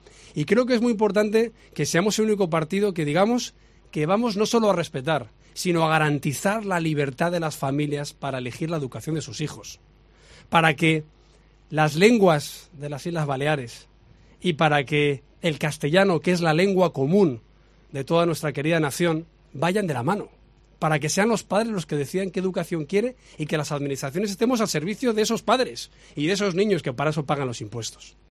En el mitín celebrado en Palma, el líder popular ha pedido que, como Nadal, nunca se dé una bola por perdida en el partido popular.